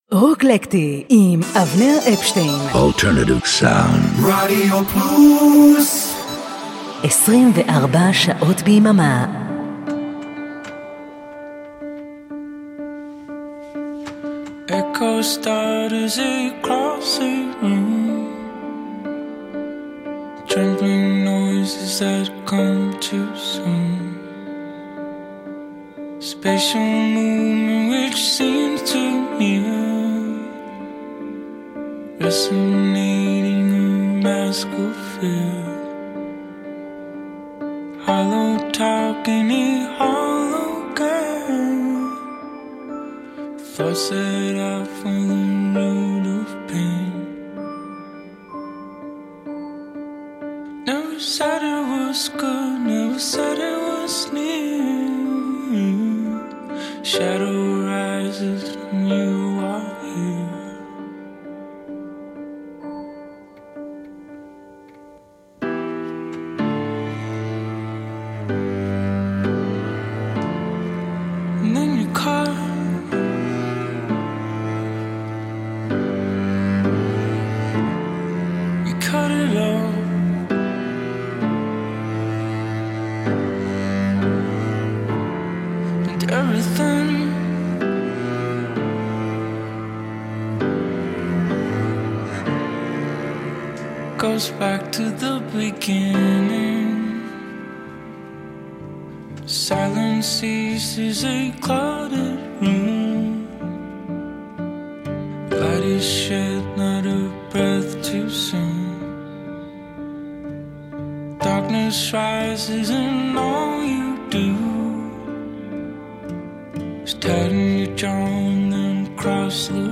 acoustic alternative rock classic rock soul/r&b